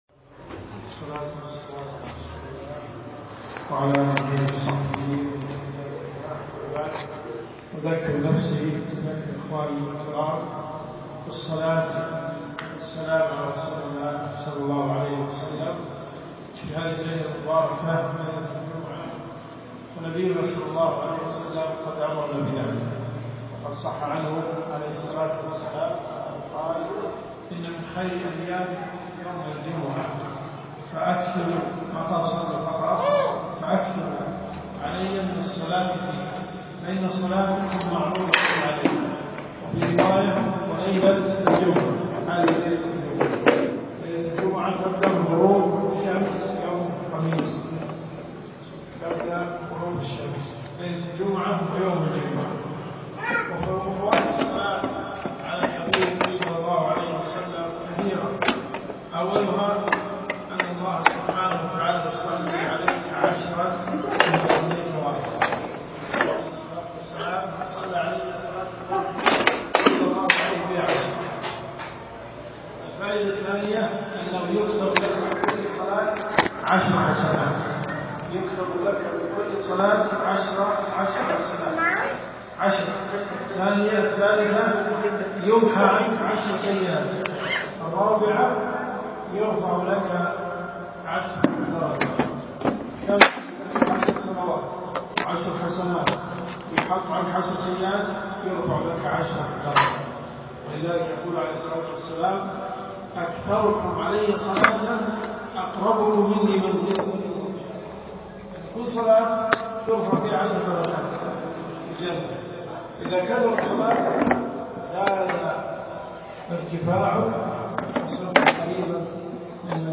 كلمات المساجد . مغرب الخميس . فوائد الصلاة على النبي محمد صلى الله عليه سلم .